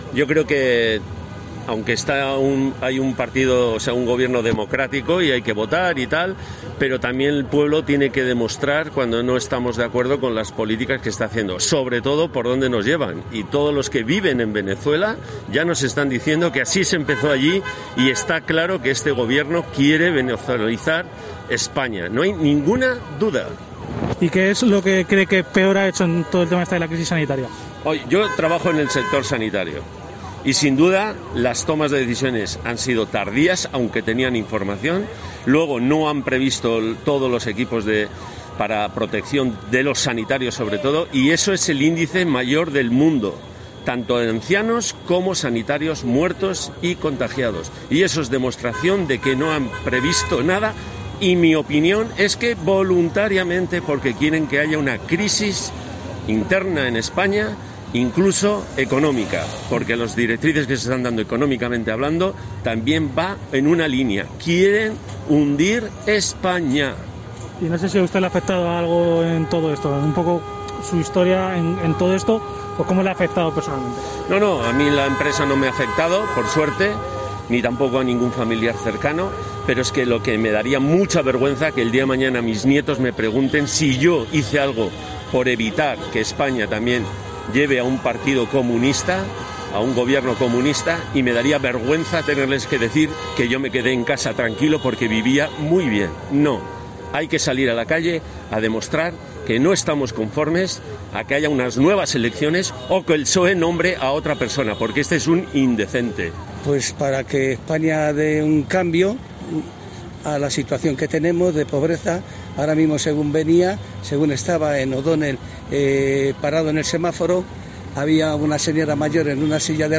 A través de centenares de coches en toda España, los participantes de la protesta convocada por Vox cuentan en COPE las razones para manifestarse